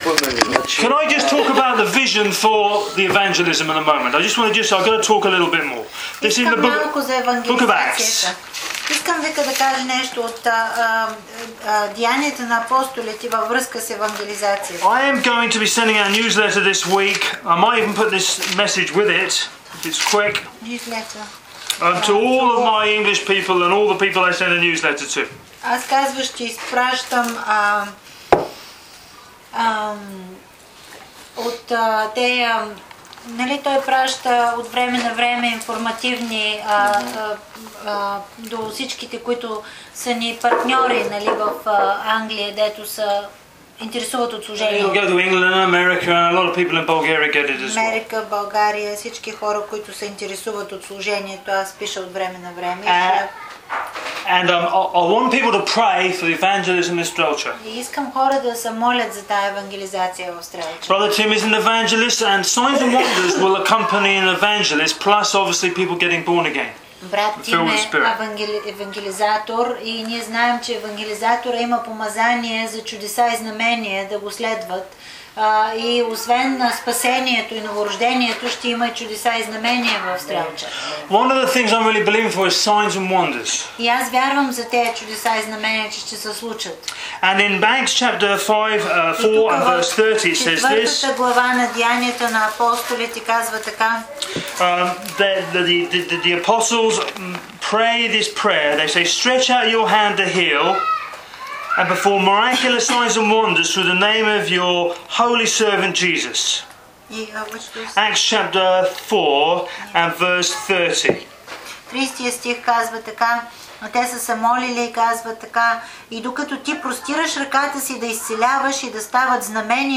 Short preparation message given to Jutvari pastors prior to evangelism, English with Bulgarian translation